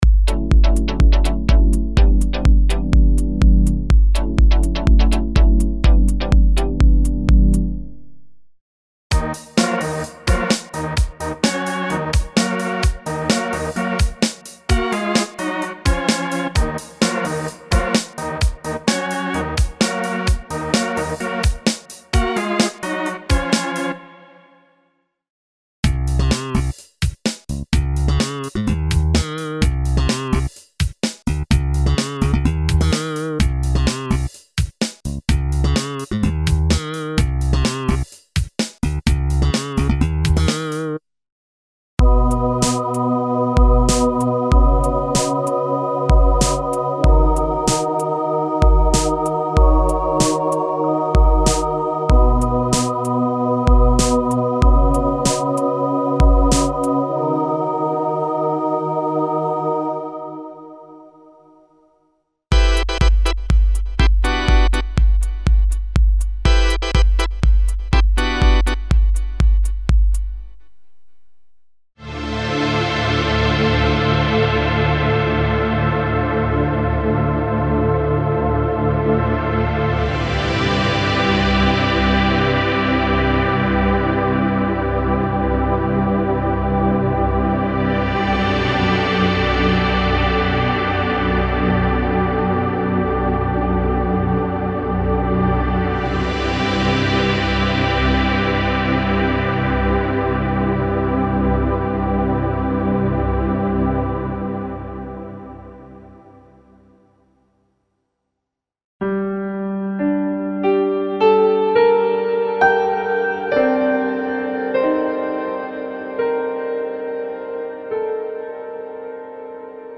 A complete collection of sound banks for Pop, Rock, Jazz, Movie, Modern Dance music, Ambient and Ethno.
K-Works - Workshop - LE - Special Bundle (Kurzweil K2xxx).mp3